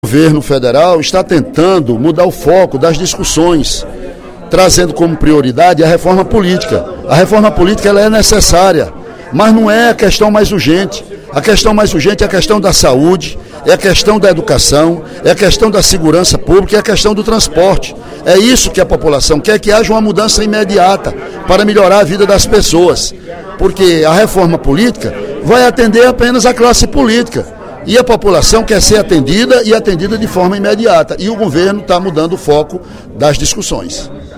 Durante o primeiro expediente da sessão plenária desta quarta-feira (03/07), o deputado Ely Aguiar (PSDC) acusou o Governo Federal de tentar desvirtuar o foco das discussões, quando prioriza reforma política em detrimento dos serviços públicos. Mesmo destacando a relevância da reforma, o parlamentar entende que não é a questão mais urgente no País, acometido pela precariedade em áreas essenciais como saúde e educação, além de segurança pública e transporte.